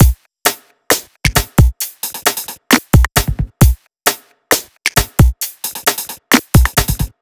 HP133BEAT2-R.wav